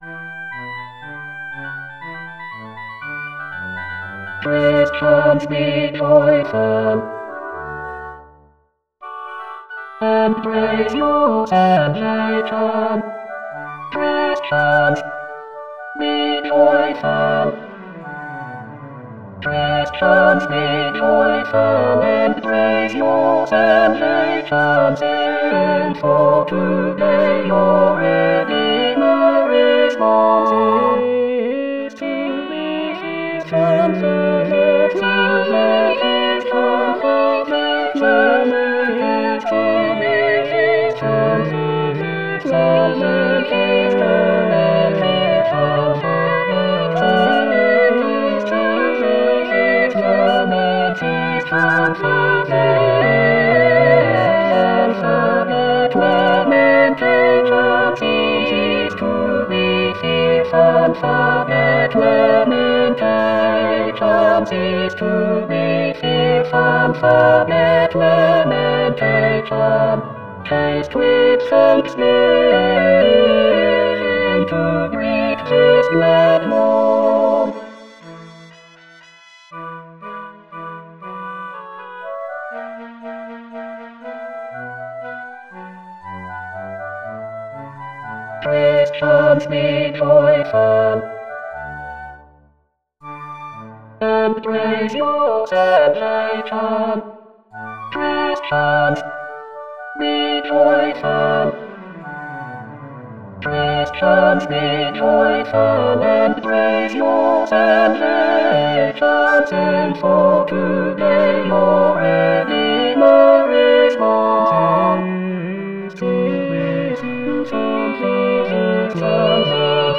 Full choir